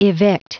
Prononciation du mot evict en anglais (fichier audio)
Prononciation du mot : evict